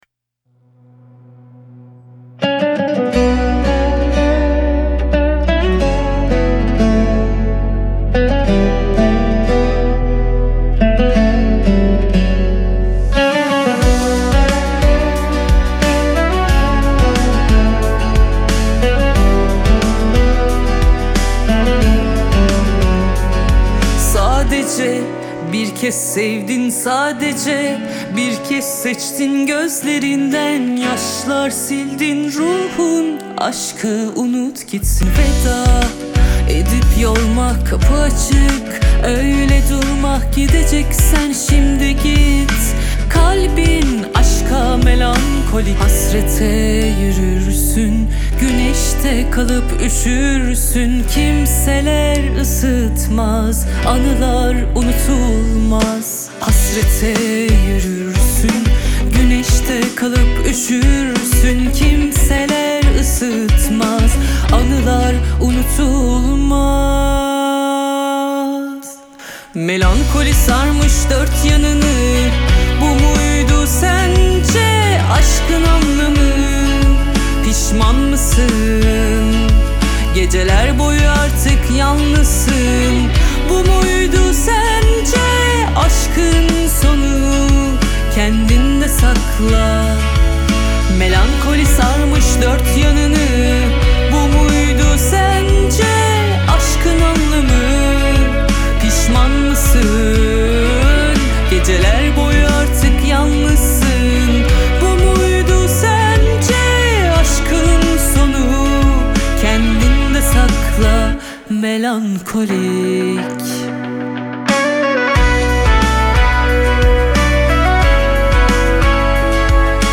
Трек размещён в разделе Турецкая музыка / Рок.